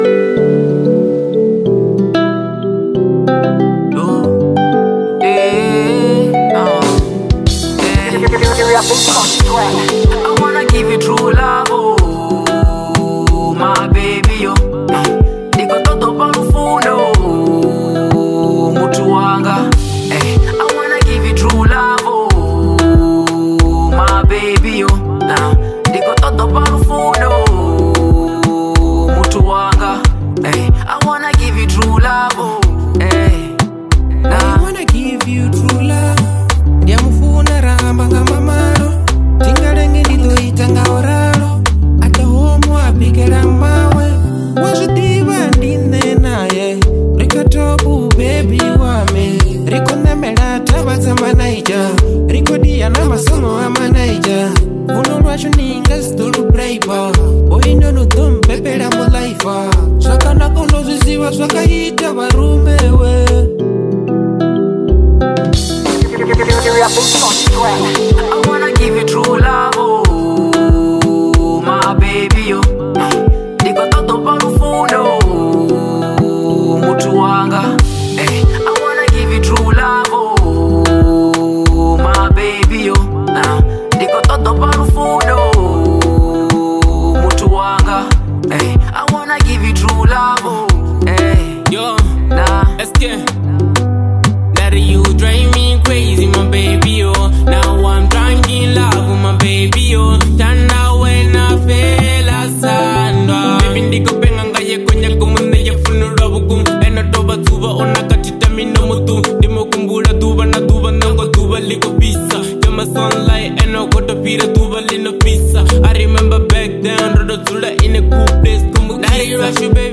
02:34 Genre : Venrap Size